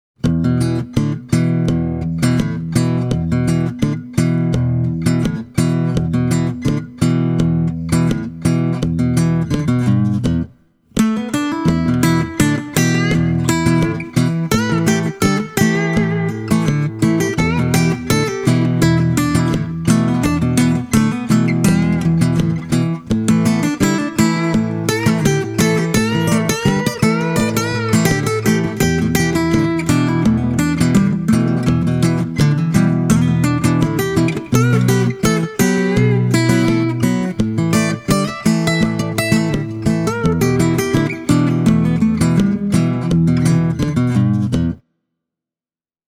Fender’s Paramount PM-2 AM is a parlour-sized steel-string acoustic (similar in size to C.F. Martin’s size 00) with a 12th fret neck joint.
Don’t get me wrong, though: The Fender PM-2 All Mahogany doesn’t sound thin, or puny, or sharp – its voice is beautiful, well-balanced and warm. It’s just that the tidier bass response will keep the bass register from swamping everything else, unlike when using certain Dreadnought models.
The PM-2 AM is a fantastic player, and it offers you the warm, but open tones, you’d associated with a quality exponent of the 00-size guitar.